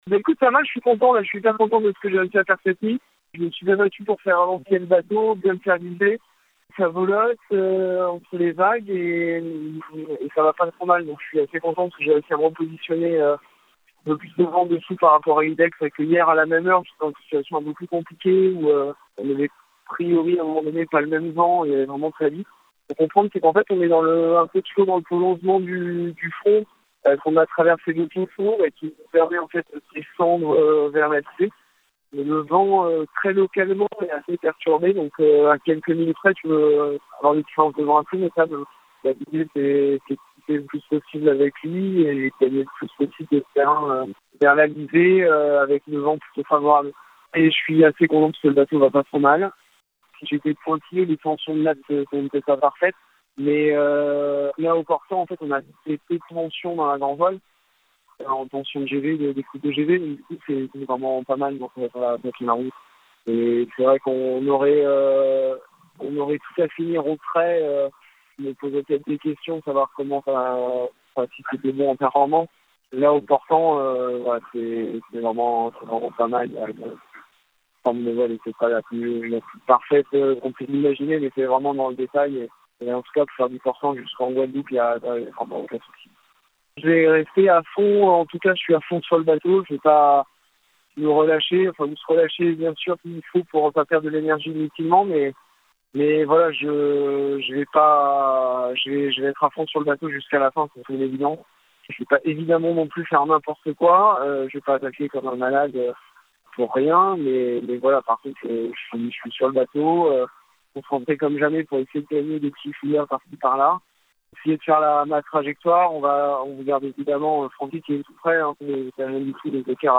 Il reste encore 1800 milles à parcourir, François Gabart est en short, la voix claire à la vacation, en pleine maîtrise de son sujet.